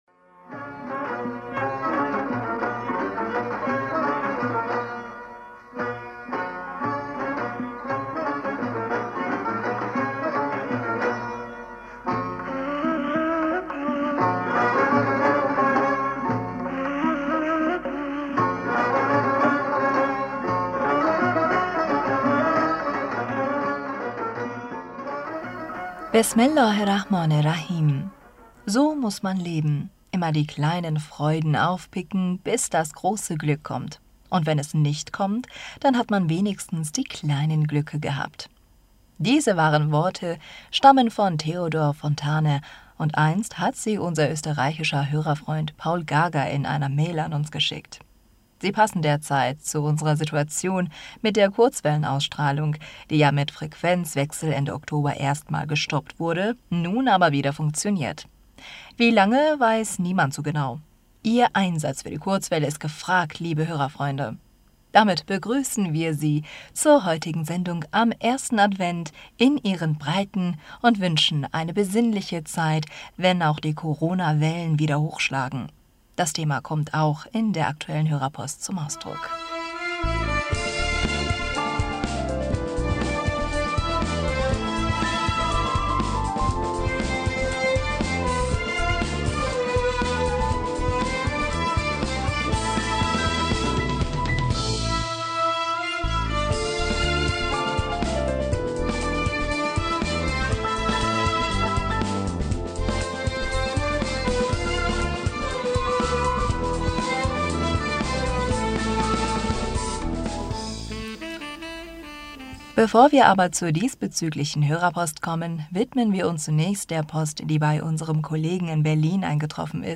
Hörerpostsendung am 28. November 2021 Bismillaher rahmaner rahim - So muss man leben!